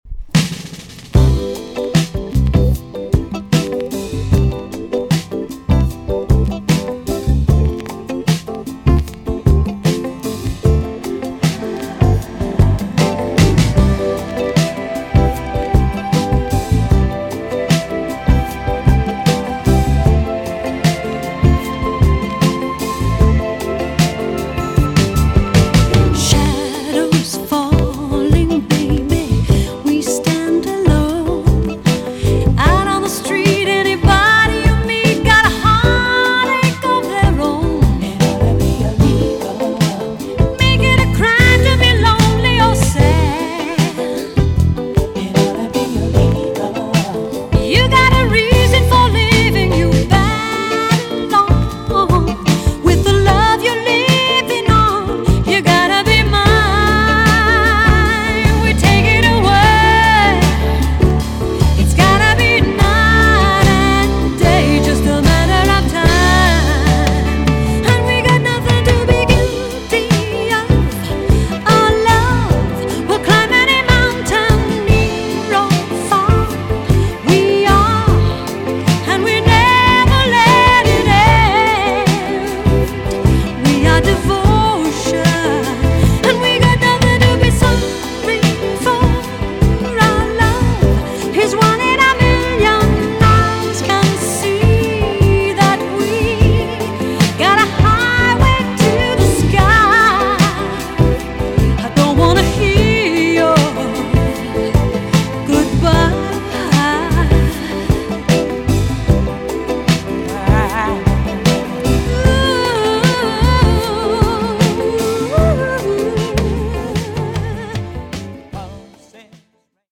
EX- 音はキレイです。
1980 , WICKED JAMAICAN SOUL TUNE!!